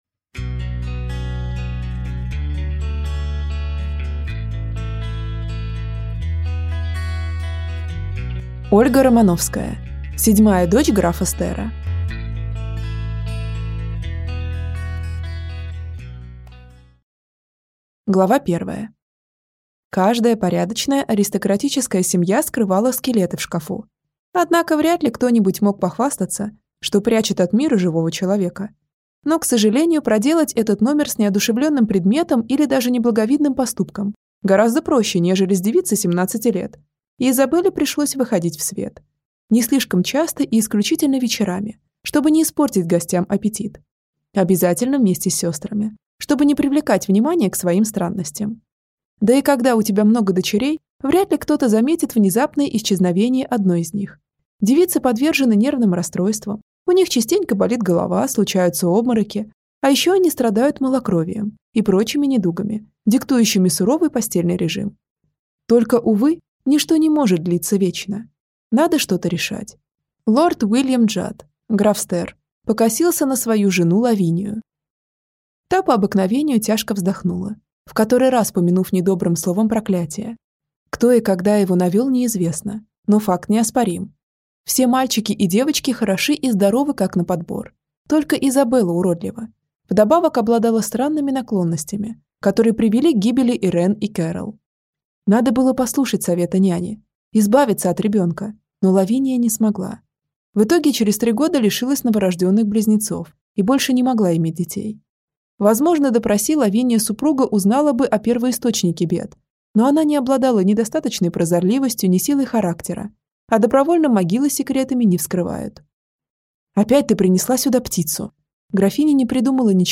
Аудиокнига Седьмая дочь графа Стера - купить, скачать и слушать онлайн | КнигоПоиск